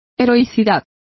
Complete with pronunciation of the translation of heroism.